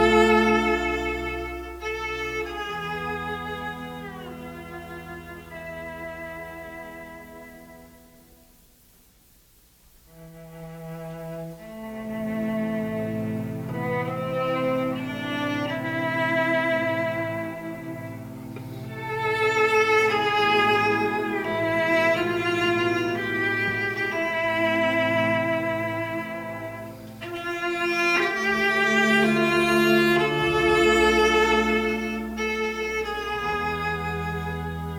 Струнные и рояль
Жанр: Классика